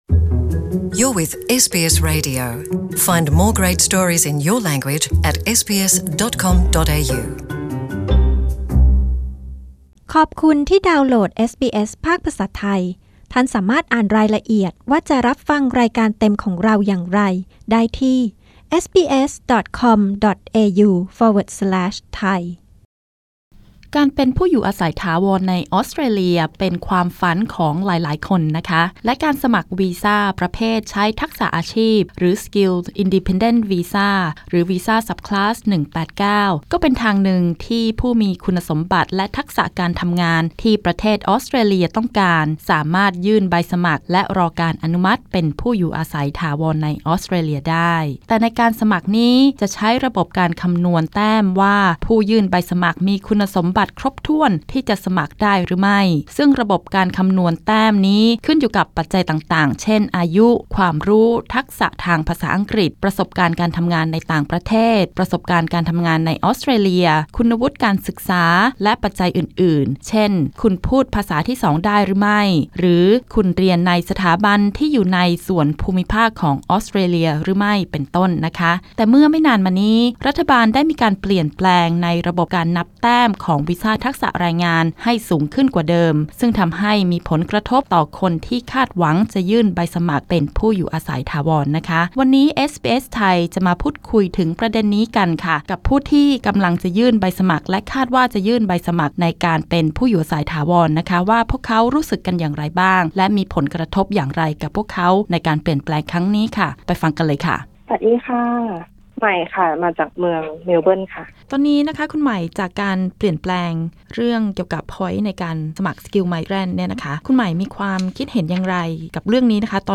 พูดคุยกับนักเรียนไทยในออสเตรเลียที่ต้องการสมัครวีซ่าทักษะในสาขาต่างๆว่าระบบนับแต้มที่สูงขึ้นเรื่อยๆส่งผลกระทบกับพวกเขาแค่ไหน มีวิธีการรับมืออย่างไร ด้านเอเจนท์นักเรียนเผยเรื่องนี้ไม่ส่งผลกับจำนวนนักเรียนไทยที่เลือกมาเรียนต่อที่ออสเตรเลีย